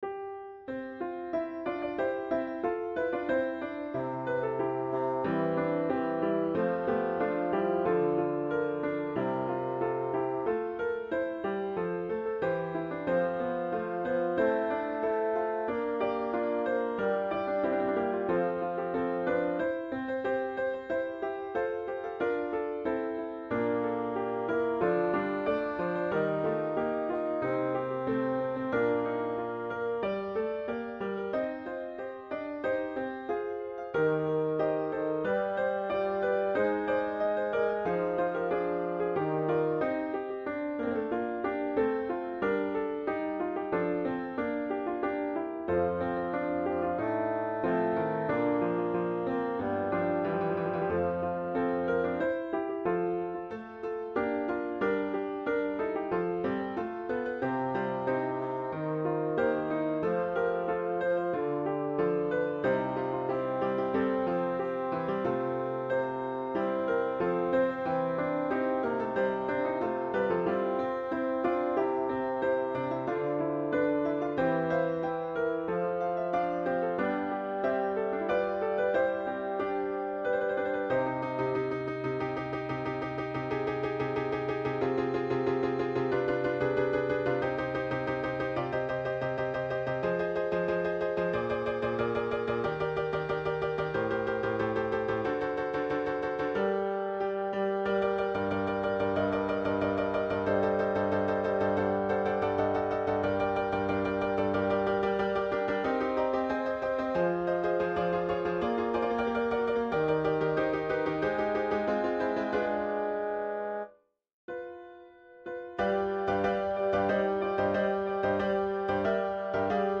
Bass
5-miserere_de_zelenka_iii_bass.mp3